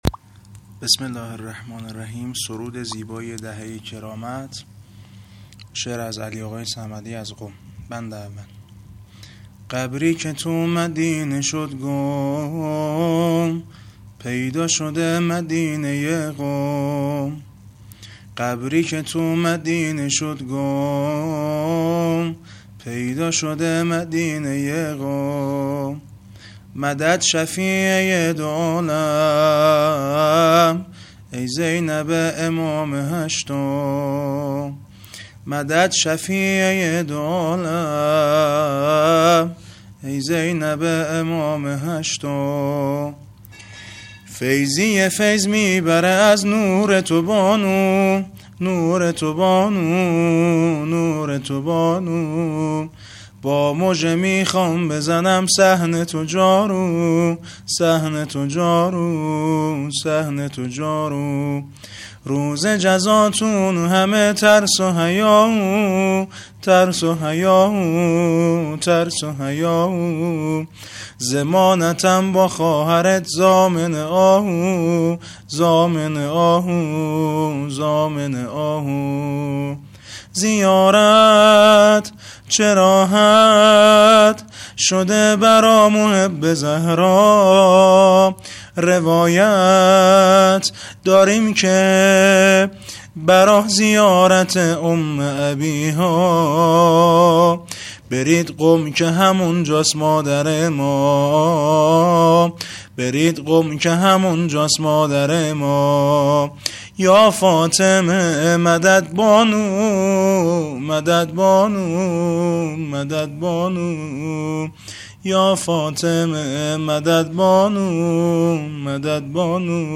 ✳سرود زیبای دهه کرامت ✳
?سبک : توی دلا خیلی عزیزی?